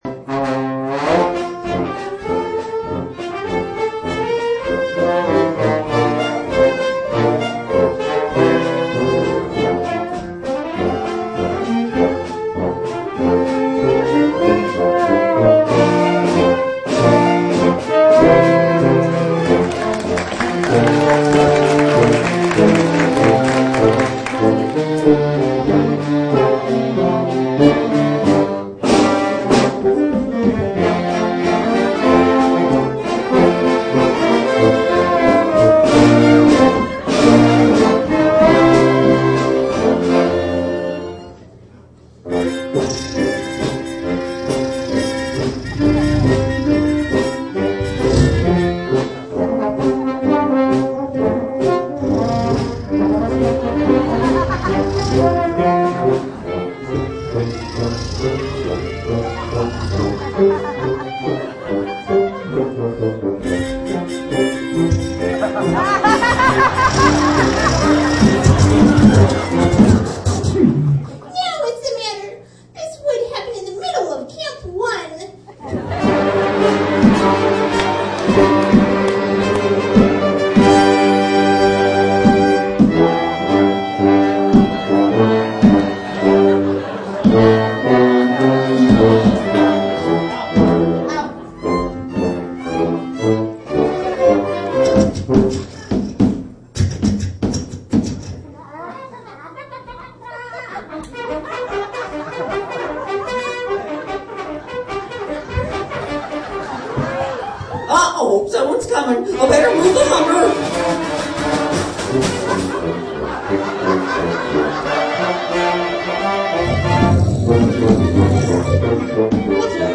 Lark Camp | Photo & Video Links Page - world music & dance celebration
2008 Sound File of the Betty Boop Silent Movie Performance